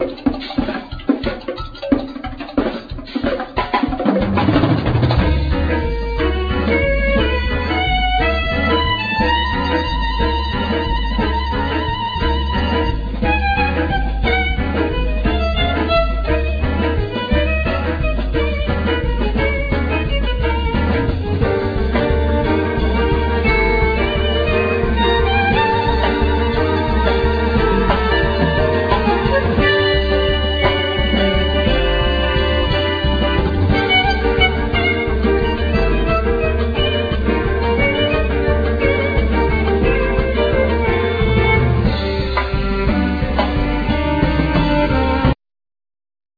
Accordeon,Vocal
Violin,Viola
Keyboards,Vocals
Electric & Acoustic Bass,Guiro
Vibraphone,Marimba,Glockenspiel,Percussion
Drums,Percussion